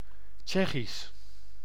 Ääntäminen
Ääntäminen France: IPA: [tʃɛk] Haettu sana löytyi näillä lähdekielillä: ranska Käännös Konteksti Ääninäyte Substantiivit 1.